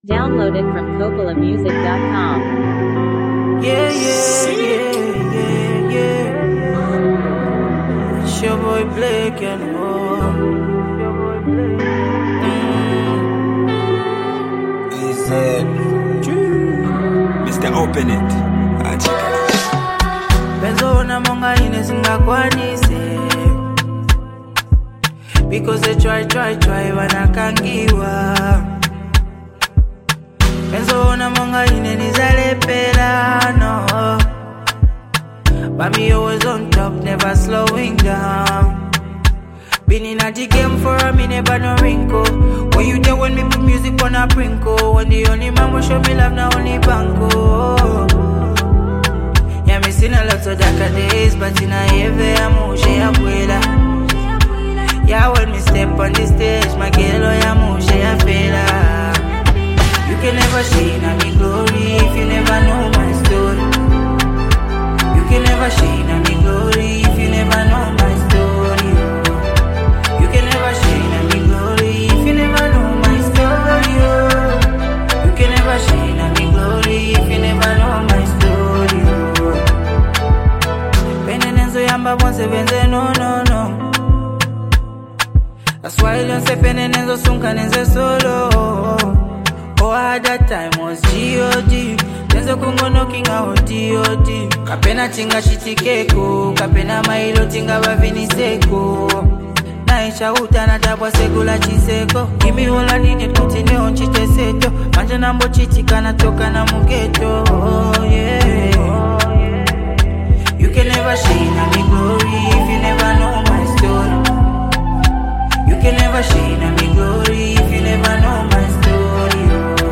GOSPEL SONGSMusic